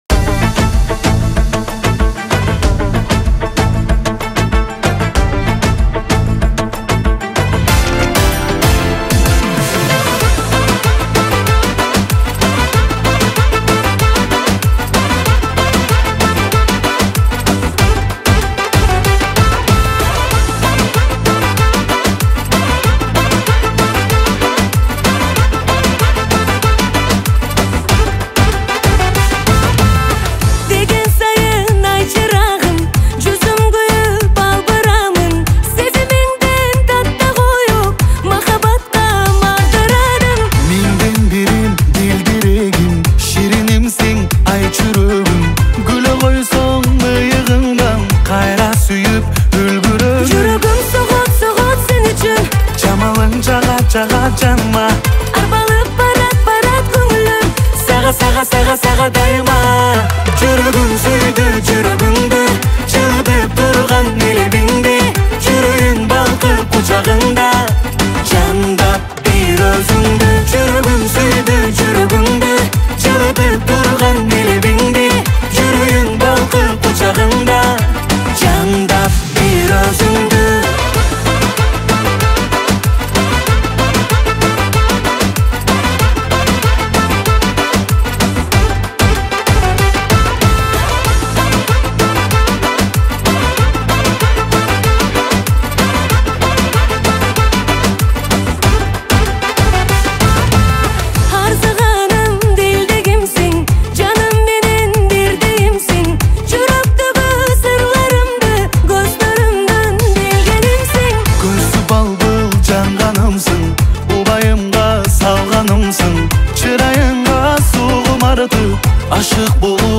• Категория: Кыргызские песни